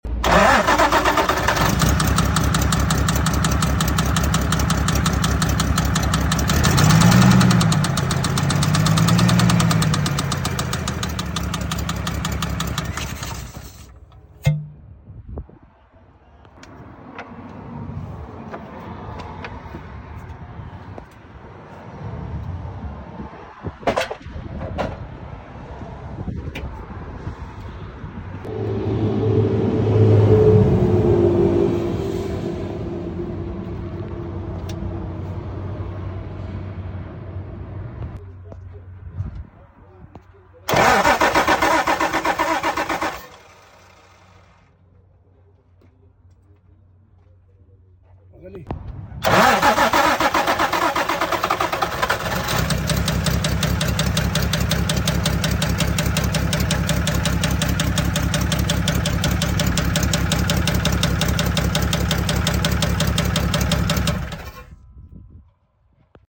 Sound in an engine 🤔 sound effects free download